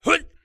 人声采集素材/男3战士型/ZS蓄力2.wav